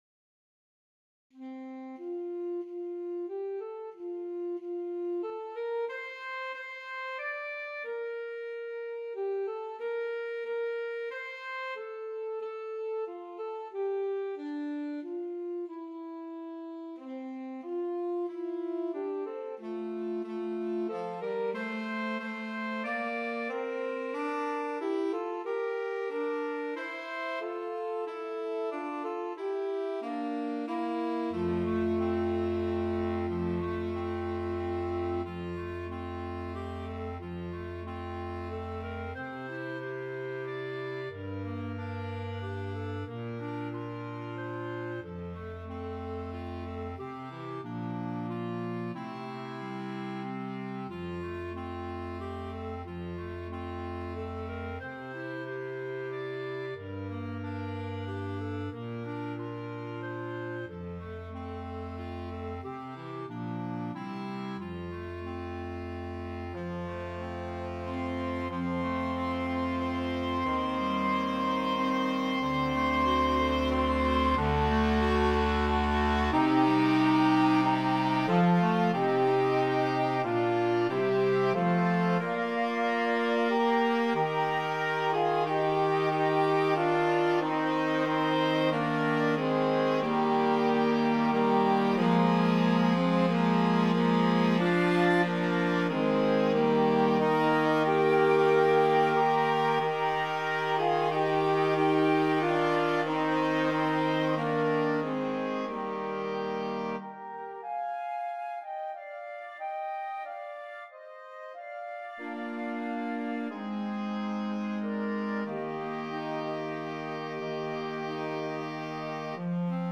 for Woodwind Choir